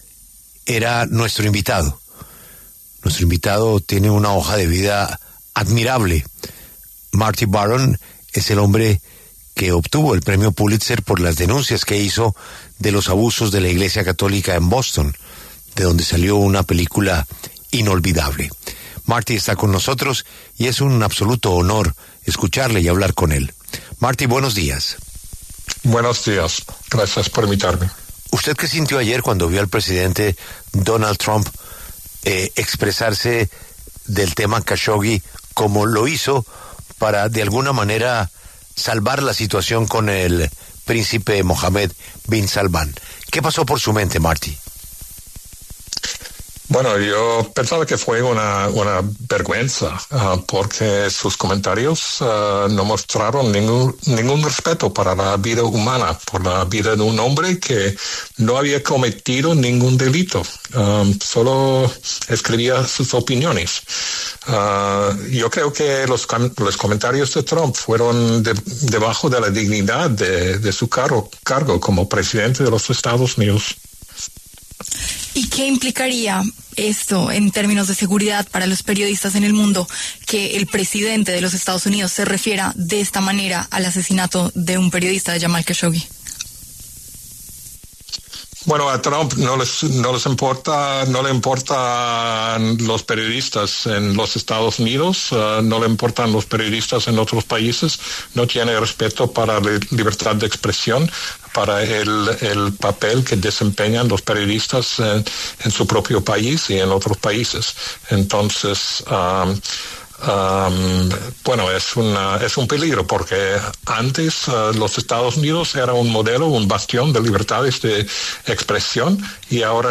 Para hablar sobre el tema, el periodista Baron, quien además dirigía el Washington Post al momento del asesinato de Jamal Khashoggi, pasó por los micrófonos de La W.